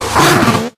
SWINUB.ogg